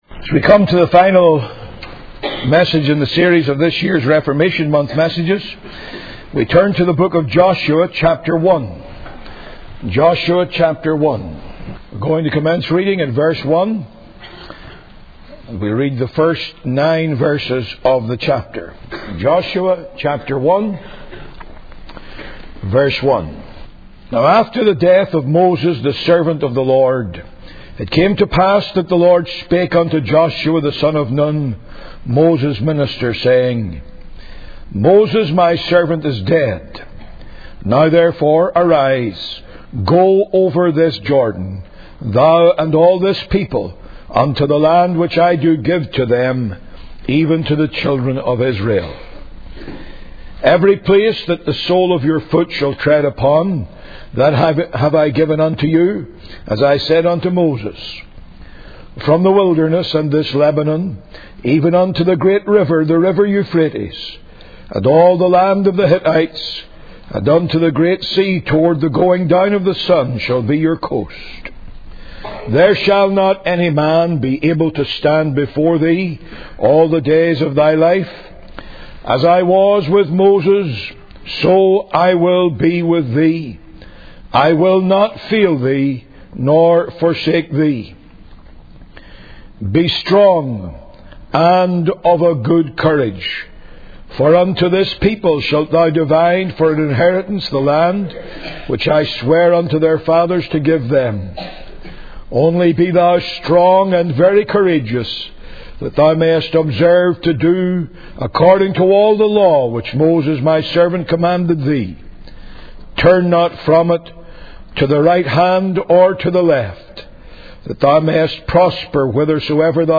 In this sermon, the preacher, Martin Lloyd Jones, begins by acknowledging that he was initially hesitant to preach because he thought it might be too long for the audience.